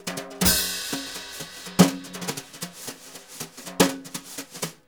Index of /90_sSampleCDs/Univers Sons - Jazzistic CD 1 & 2/VOL-1/03-180 BRUSH